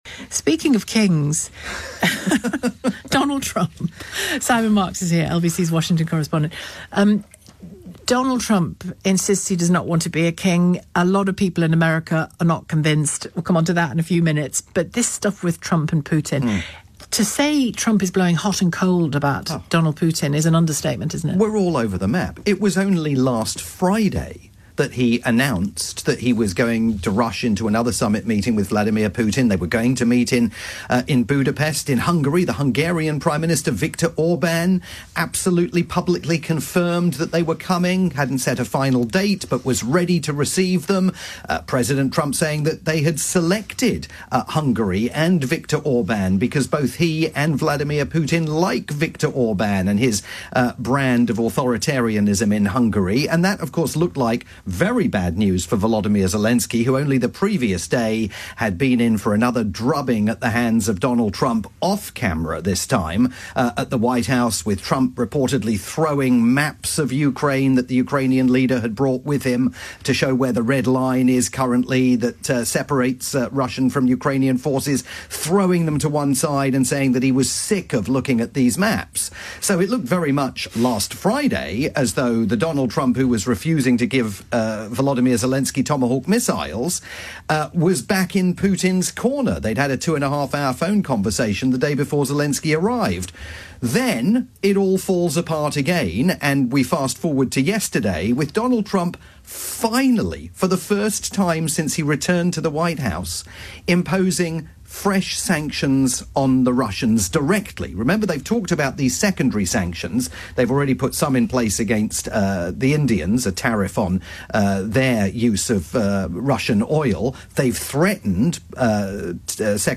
live update for Shelagh Fogarty's afternoon programme on the UK's LBC.